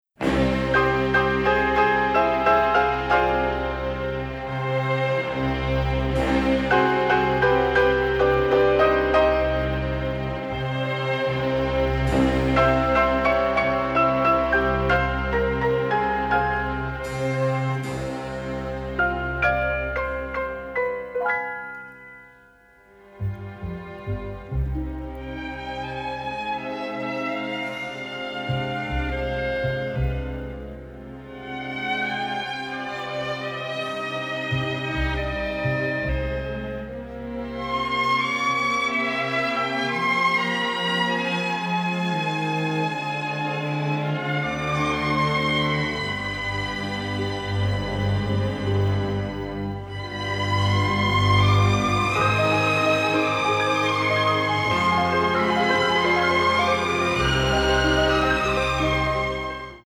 psychedelic cult classics